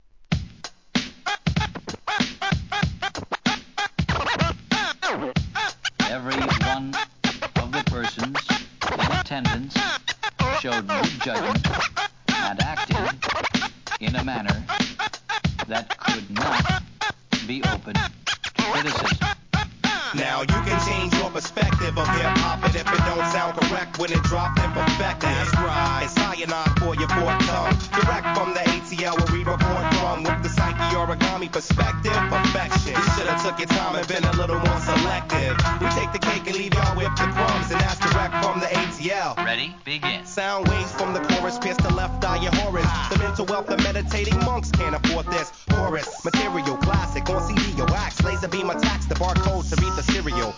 HIP HOP/R&B
2002年、アトランタ・アンダーグランド!!